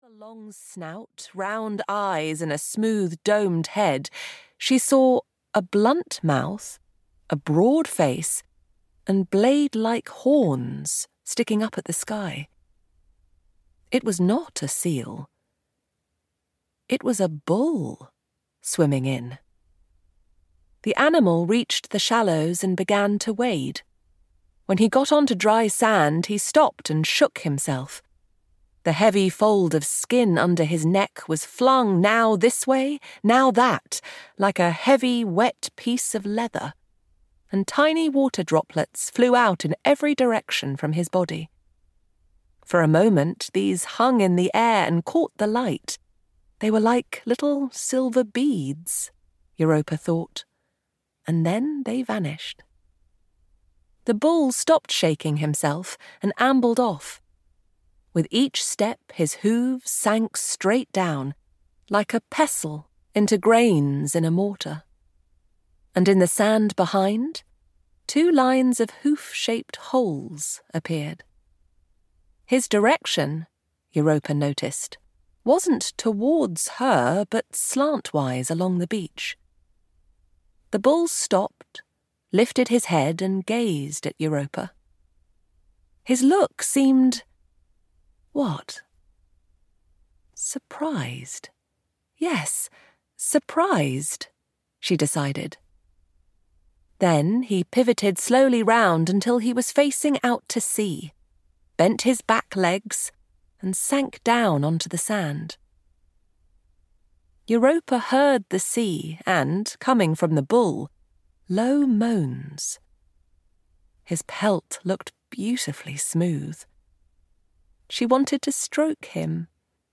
I, Antigone (EN) audiokniha
Ukázka z knihy